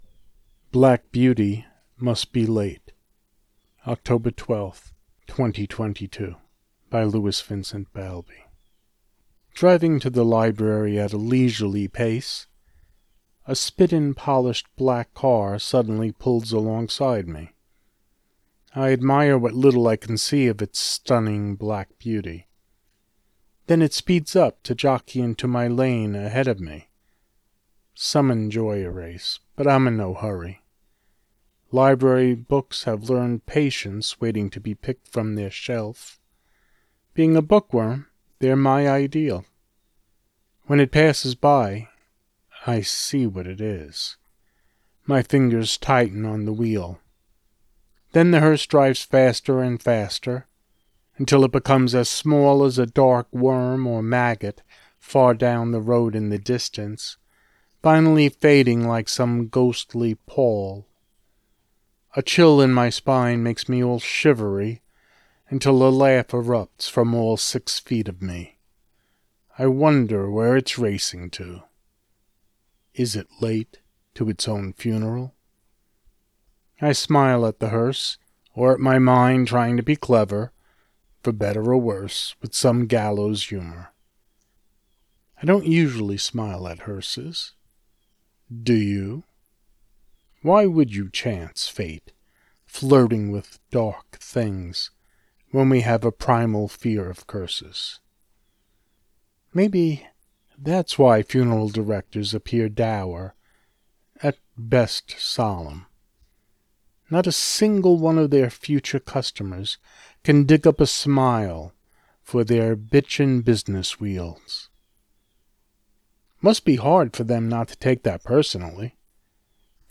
AUDIO: Black Beauty Must Be Late read